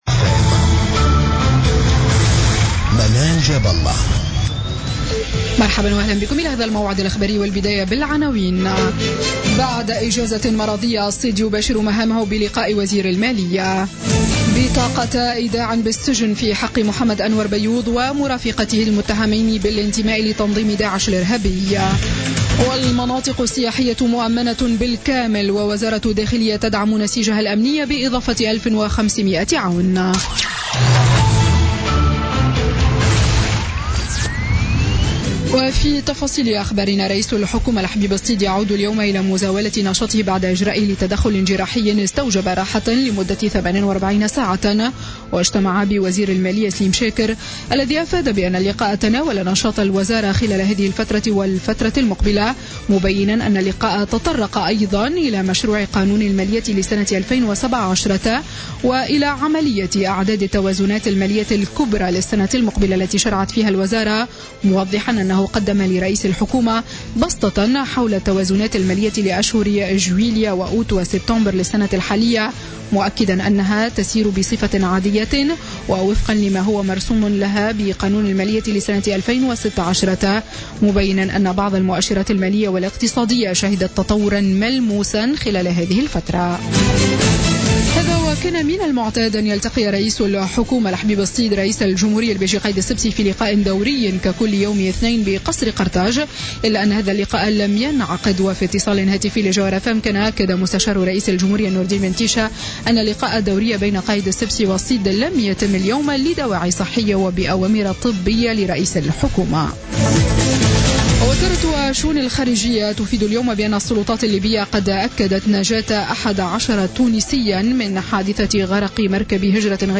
نشرة أخبار الخامسة مساء ليوم الاثنين 4 جويلية 2016